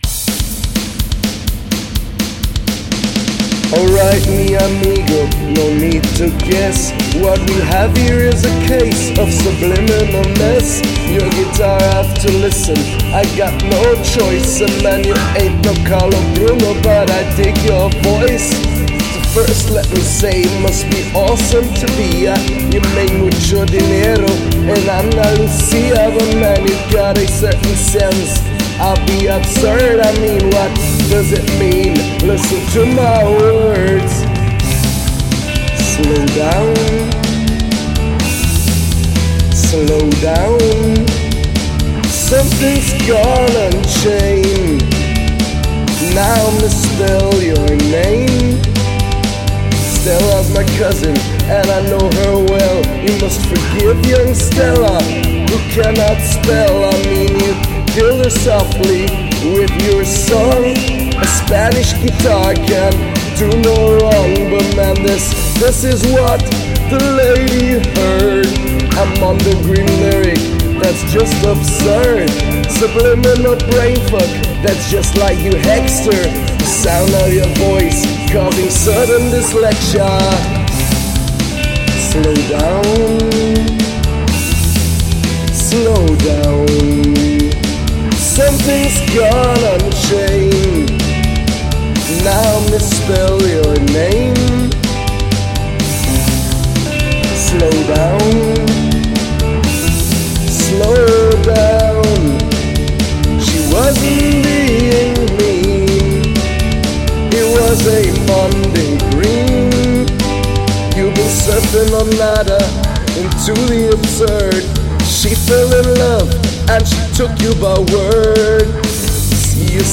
Vocals killed this one for me.